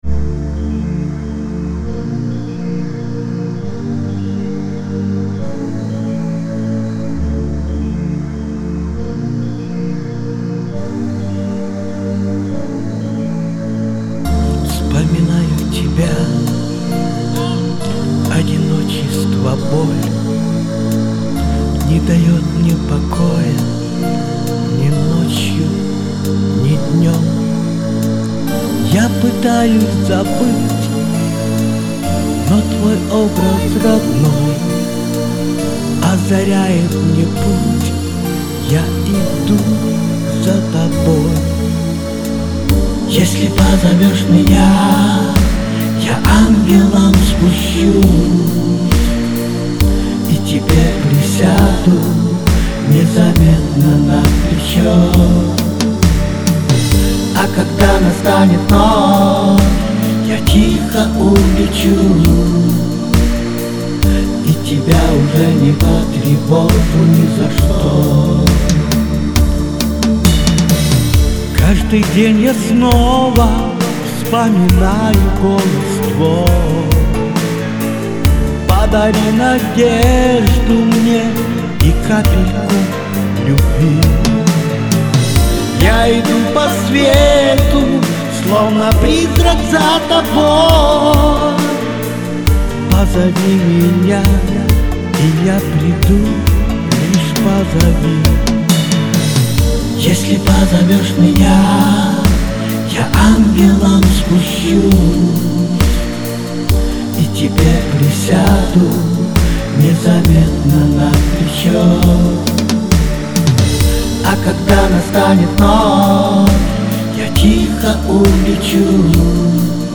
Ни одна из песен не доведена до конца потому , что обе они не подвергались тщательному сведению и мастерингу .